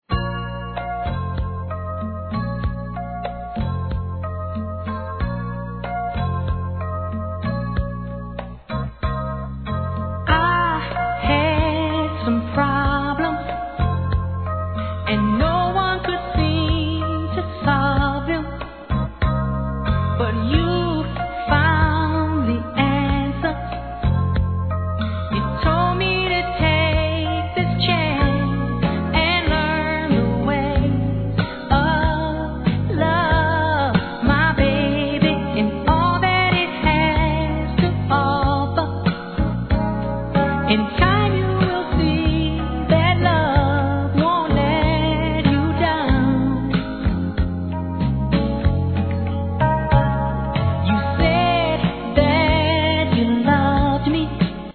¥ 1,650 税込 関連カテゴリ SOUL/FUNK/etc...